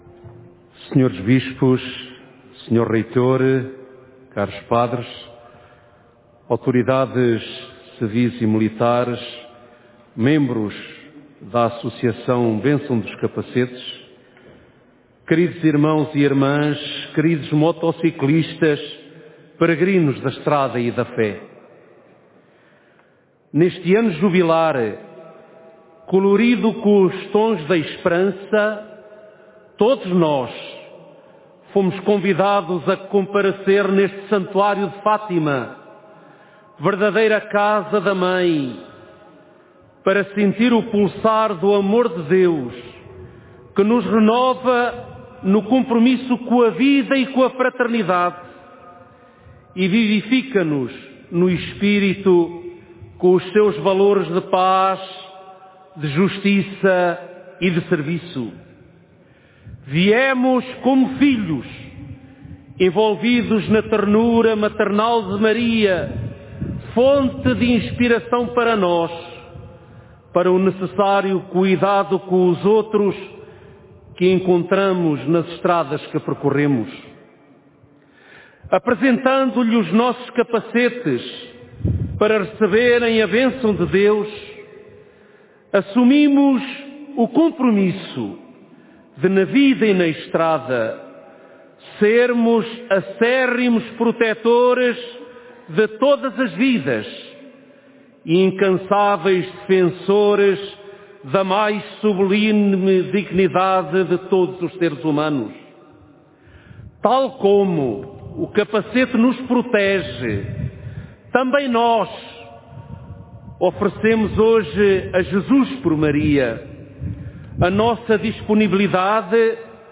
homilia-de-druivalerio.mp3